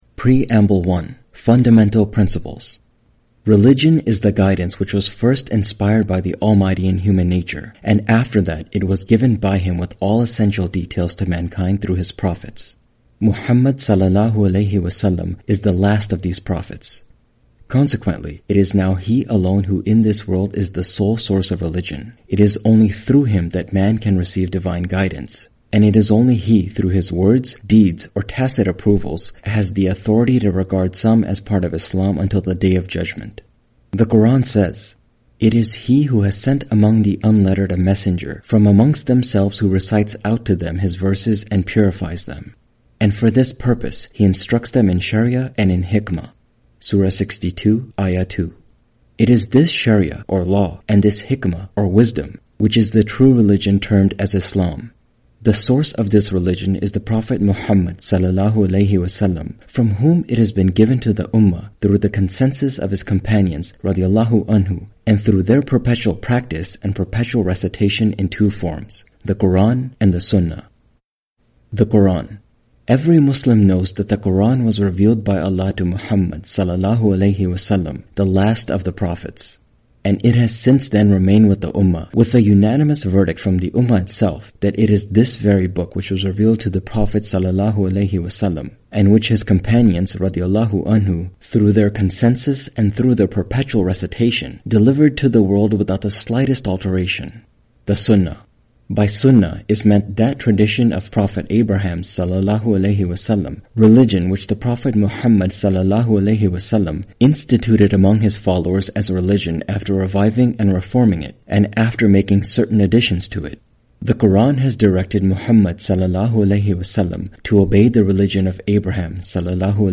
Audio book of English translation of Javed Ahmad Ghamidi's book "Mizan".